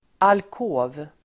Ladda ner uttalet
alkov substantiv, alcove Uttal: [alk'å:v] Böjningar: alkoven, alkover Definition: en avgränsad del av rum (avsedd för säng) (a nook off a larger room (intended for a bed)) Sammansättningar: sovalkov (bedstead recess)